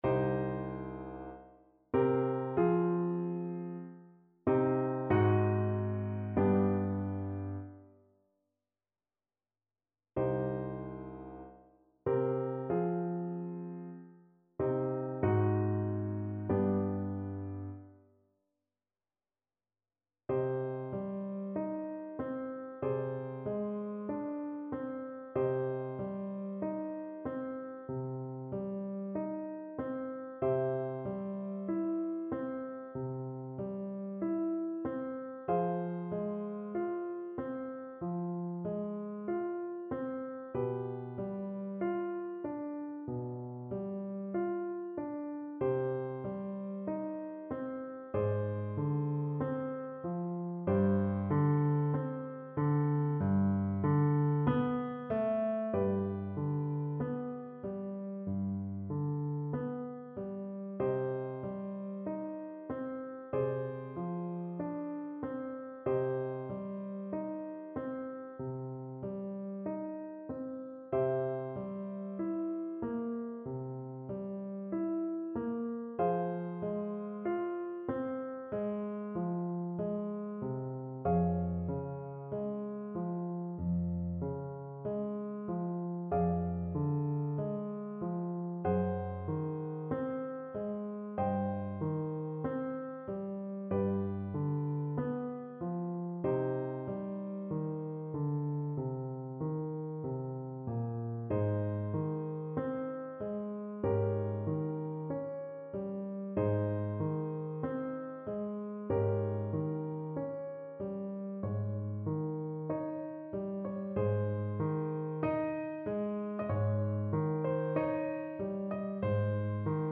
Free Sheet music for Soprano (Descant) Recorder
4/4 (View more 4/4 Music)
Lento con gran espressione
Classical (View more Classical Recorder Music)